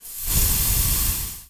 gas_leak_med_burst_01.wav